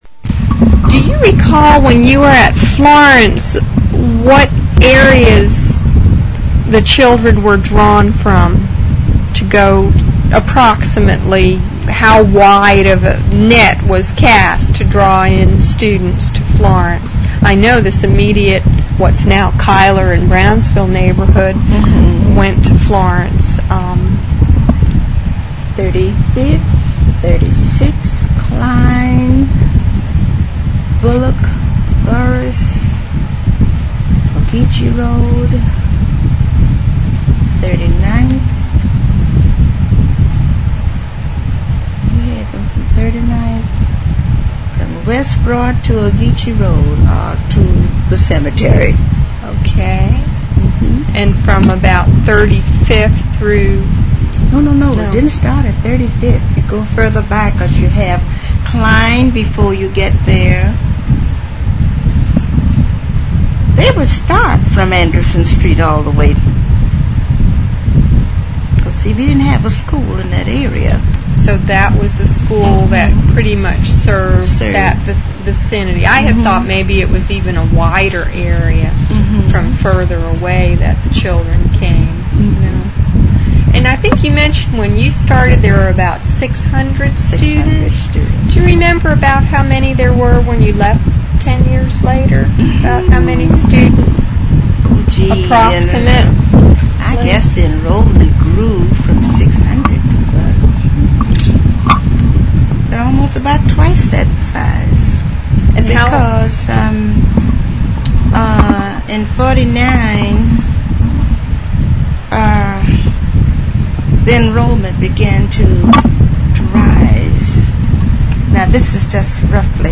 Interview
Object Name Tape, Magentic Credit line Courtesy of City of Savannah Municipal Archives Copyright Requests to publish must be submitted in writing to Municipal Archives.